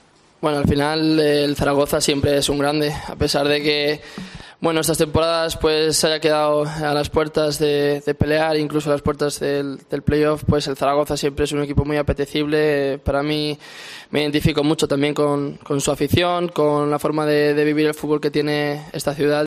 Primeras palabras de Víctor Mollejo como jugador del Real Zaragoza
En rueda de prensa, Mollejo se ha referido a sus dos nuevos compañeros en la delantera: "Son dos chicos que vienen demostrando cosas desde abajo, han hecho muy buenas temporadas y ahora que estamos aquí tenemos todos mucha hambre".